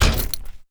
ShotImpact.wav